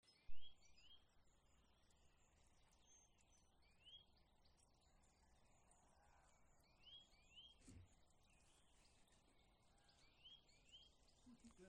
Nuthatch, Sitta europaea
StatusSinging male in breeding season